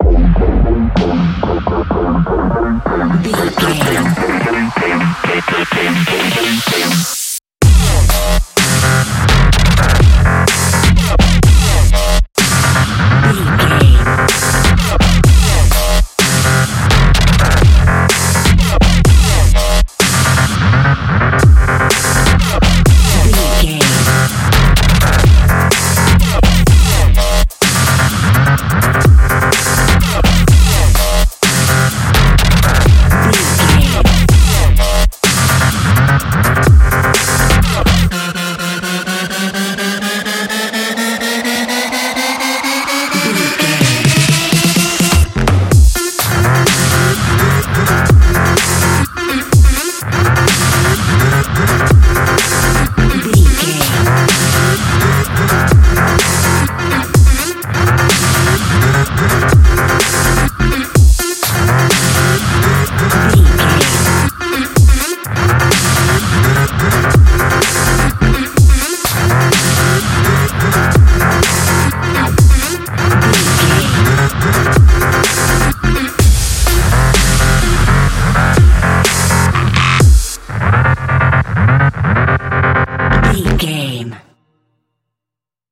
Mighty and bright sound of synths with a hard beat.
Fast paced
Uplifting
Phrygian
aggressive
driving
energetic
synthesiser
drum machine
double bass
industrial
heavy